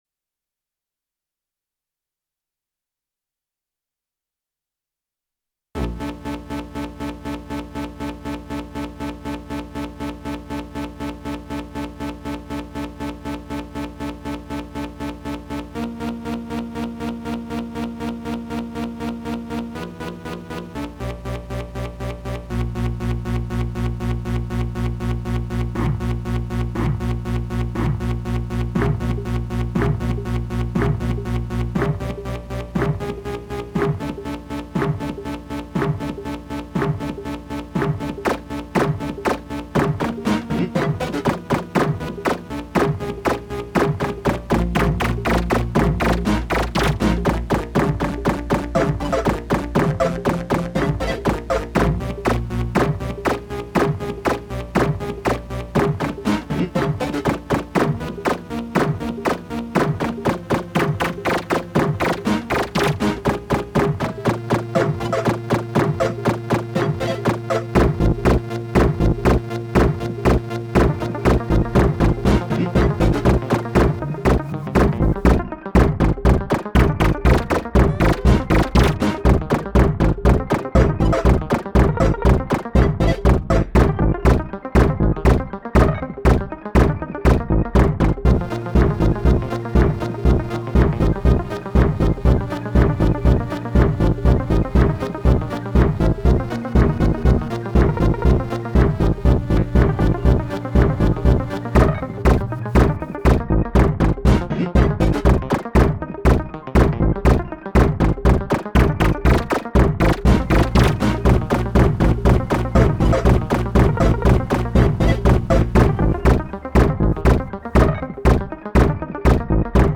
felt like doing some thing harsh… harshy? harshinging?